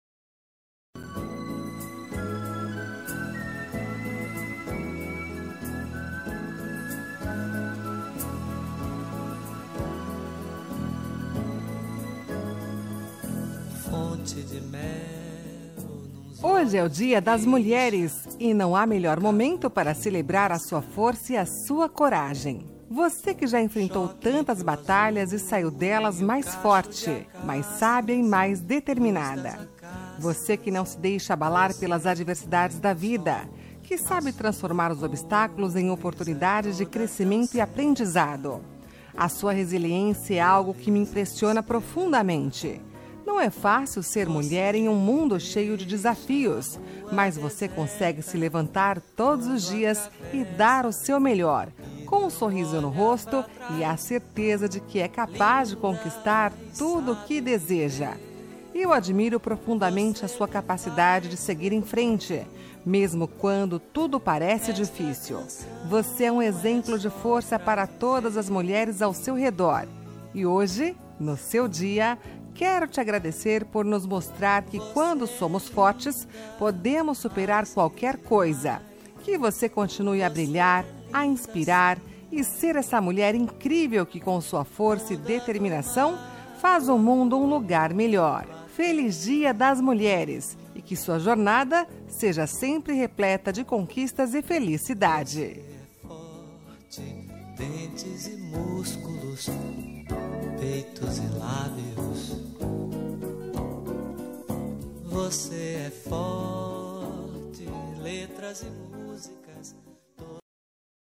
Dia da Mulher – Neutra – Feminina – Cód: 690709